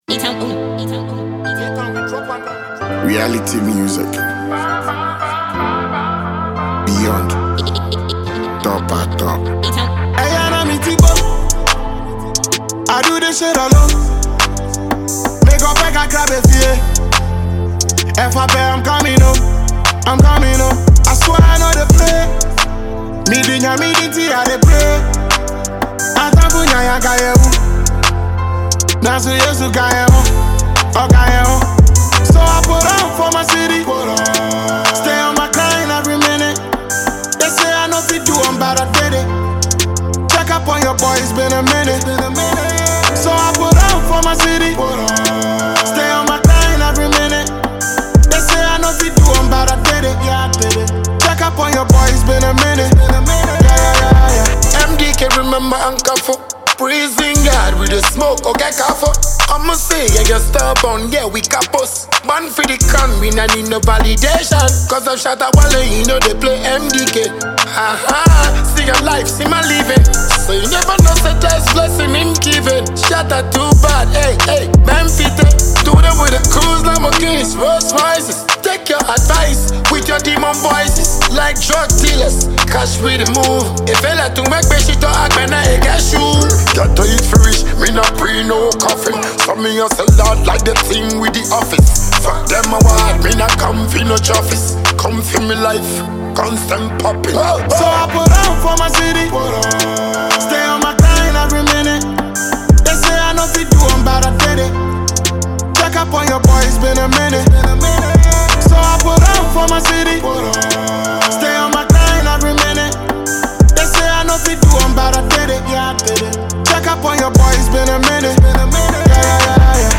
Enjoy a new tune from Ghanaian rapper